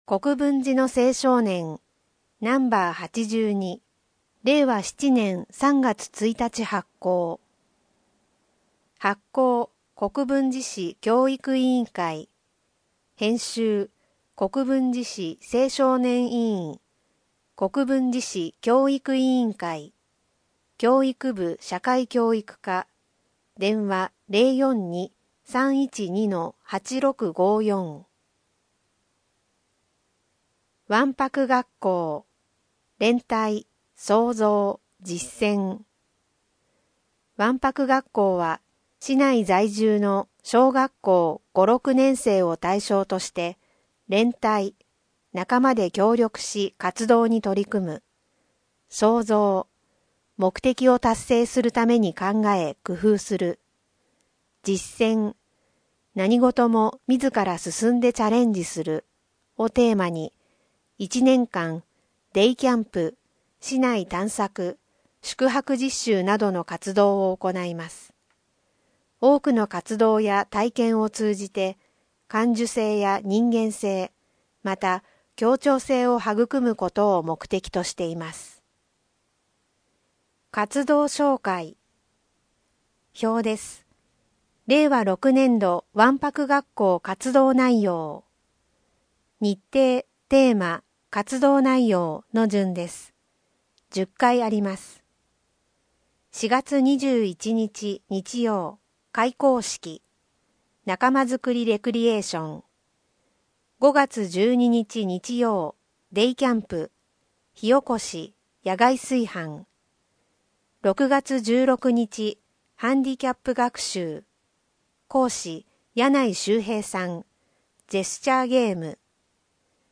82yomiage.mp3